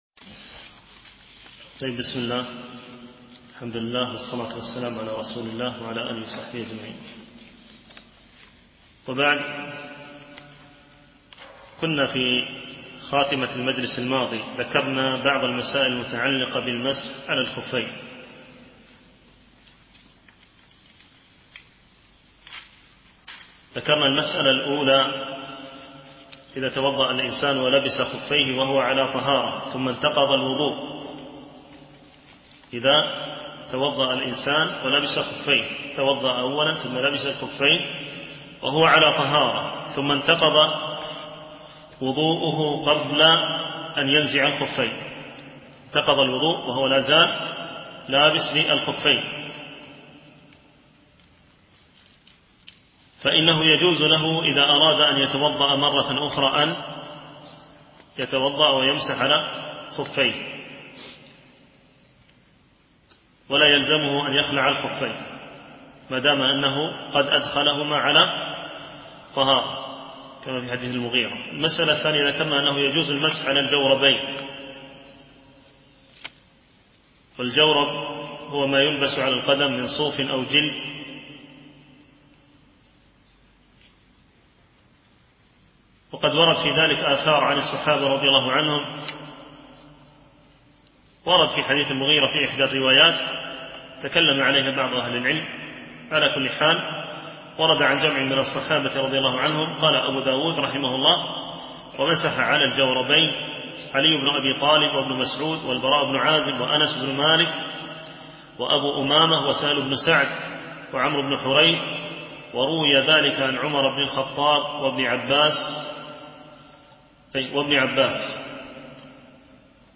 شرح كتاب بلوغ المرام من أدلة الأحكام - الدرس 9 (كتاب الطهارة، الحديث 62-70)